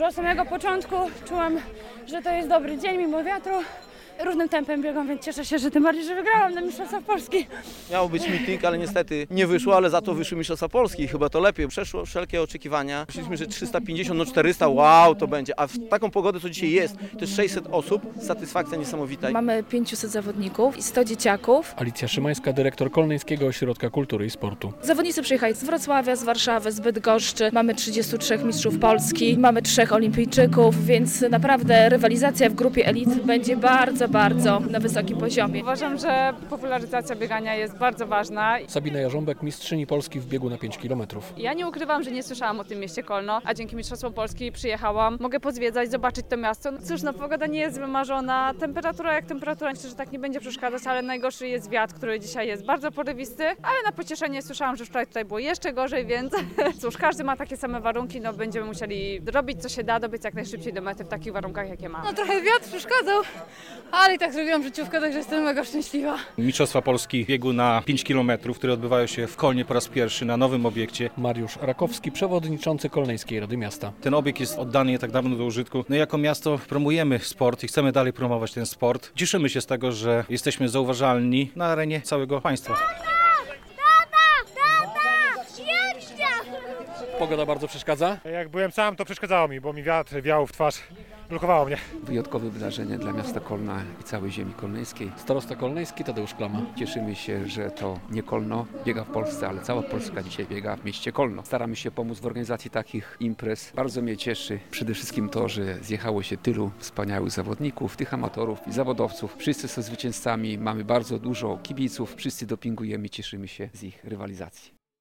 O tym jak duża jest to promocja dla miasta mówił przewodniczący Rady Miasta Kolna Mariusz Rakowski.
O, tym, że Mistrzostwa Polski to wyjątkowa impreza mówił także starosta kolneński Tadeusz Klama.